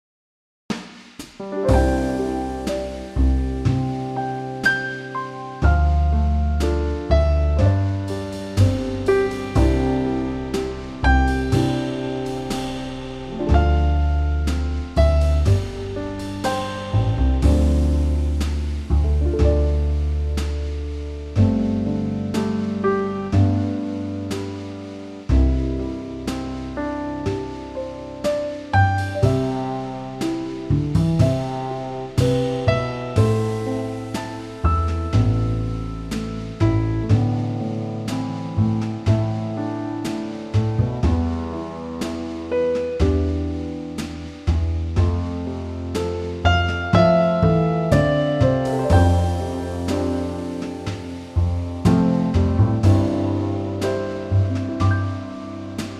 Unique Backing Tracks
key - C - vocal range - G to A
Trio arrangement
with a 4 bar intro.